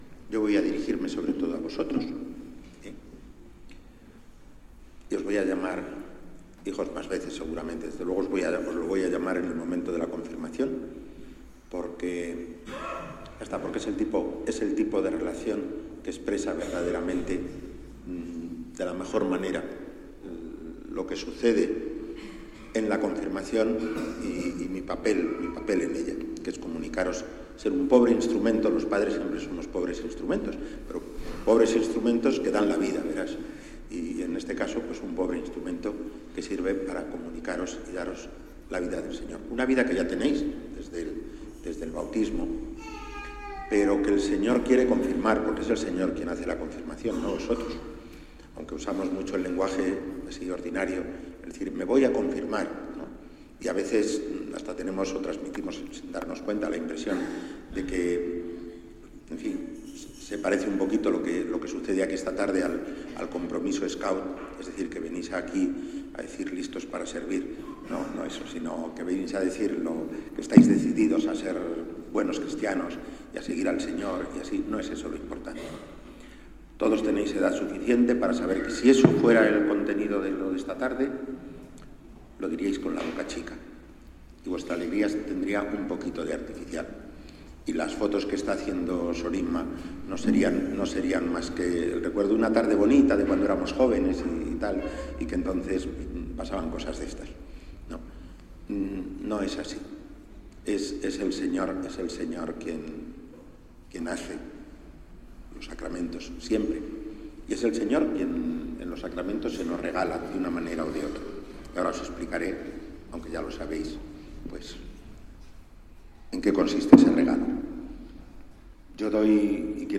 Homilía en la Eucaristía del Sacramento de la Confirmación, celebrada en la parroquia Regina Mundi de Granada, de un grupo de jóvenes de Juventudes Marianas Vicencianas y de las parroquias de Huétor Santillán y de Santa Fe.